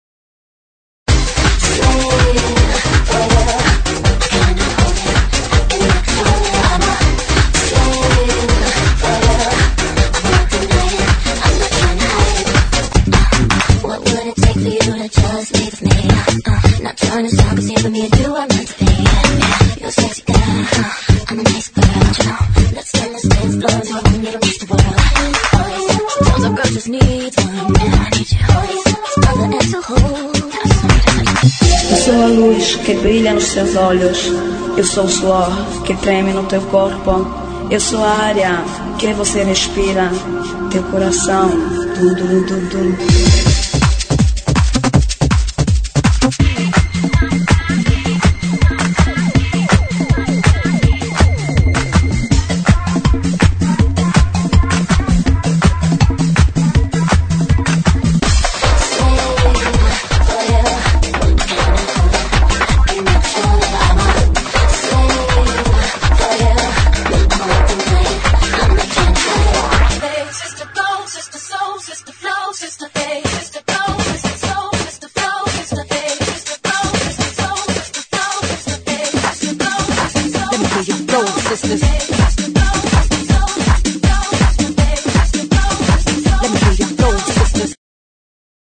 GENERO: POP – INGLES – REMIX
POP MUSIC,
POP INGLES REMIX